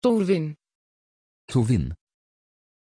Pronunciation of Torwin
pronunciation-torwin-nl.mp3